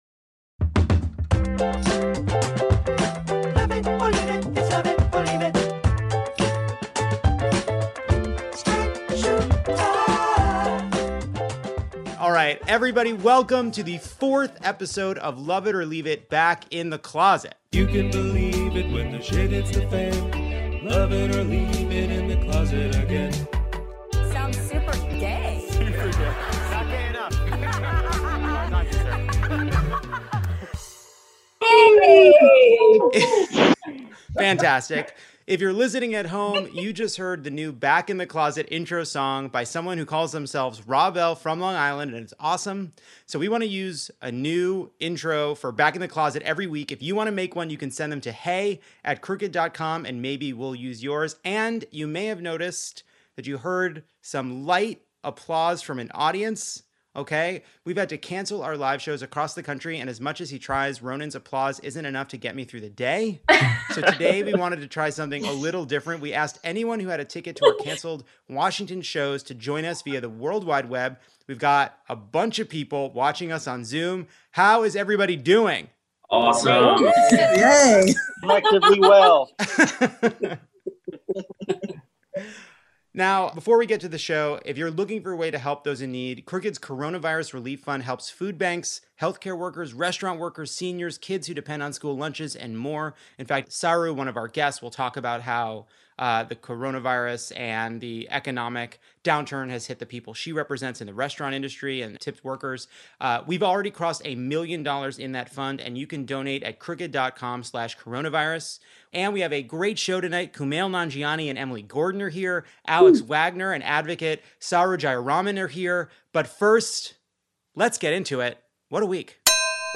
Plus we attempt a live audience with our friends in Seattle and Spokane (huge success!), and listeners share their weird new self-care rituals.